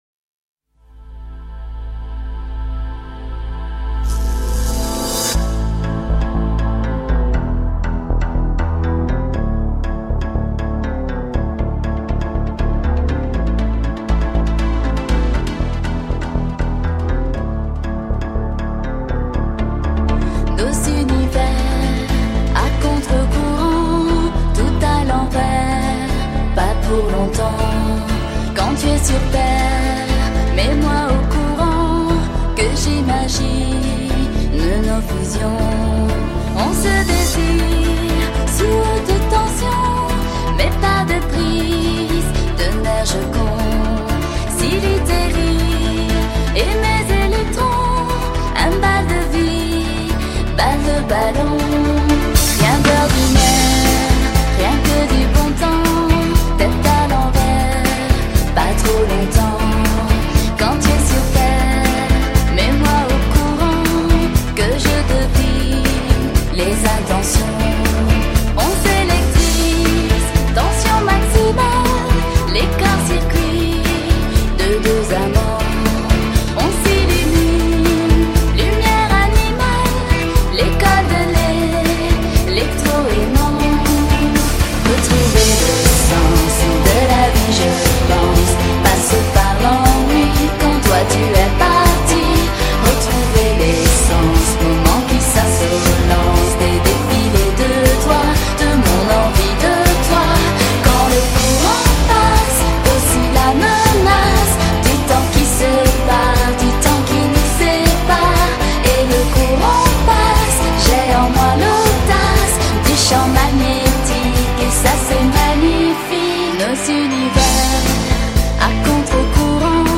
法国香颂